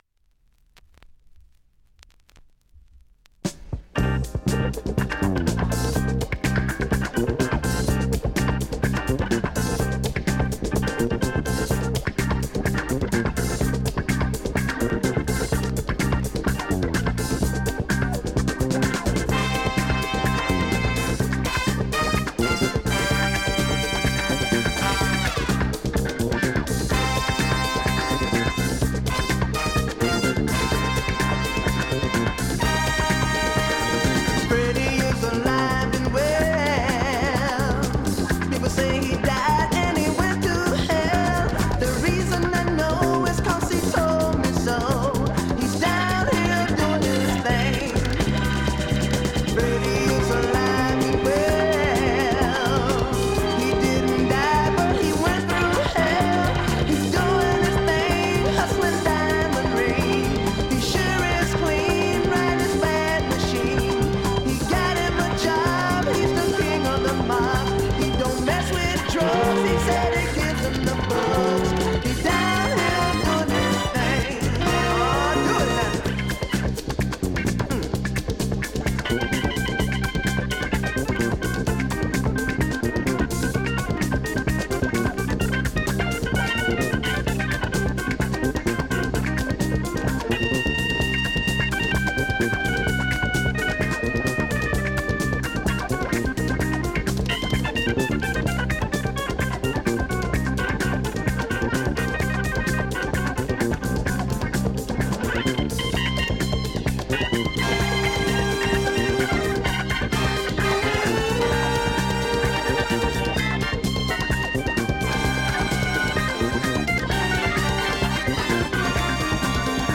現物の試聴（両面すべて録音時間９分）できます。
タイトなロング・ブレイクも含めて